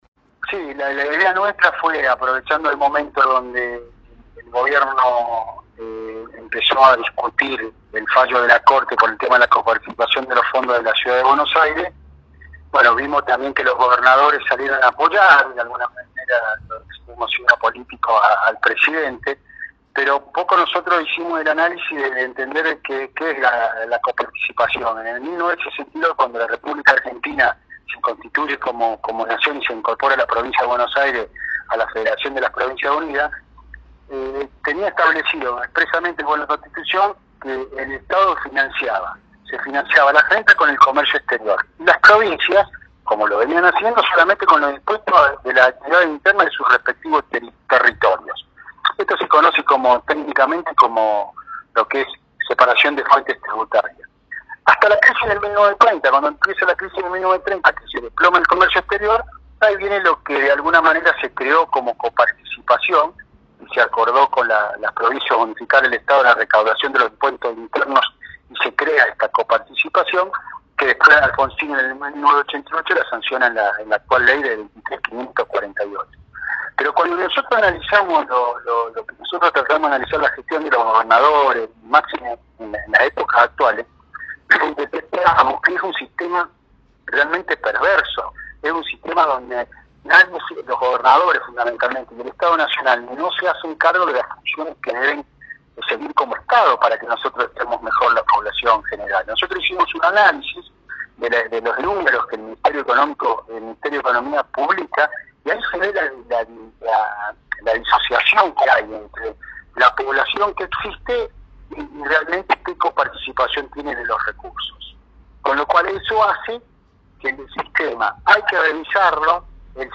En diálogo con Primera Plana de Cadena 3 Rosario